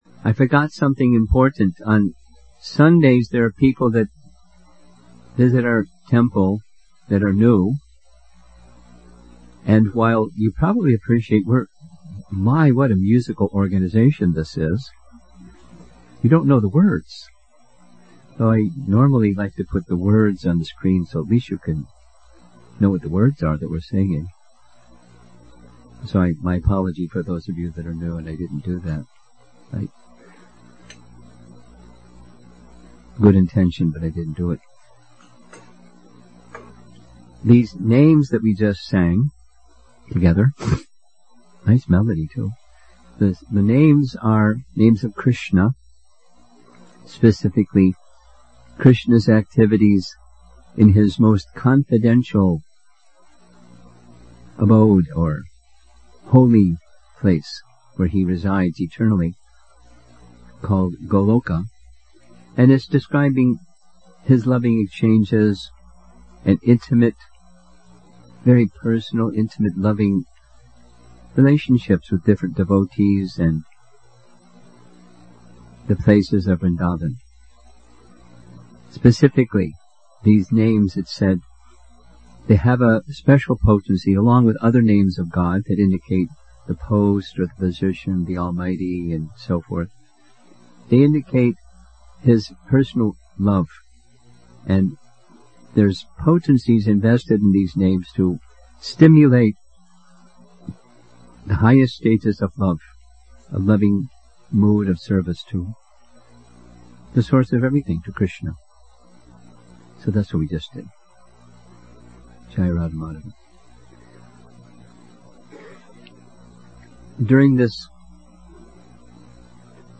Session 9 Winter Retreat Chicago December 2018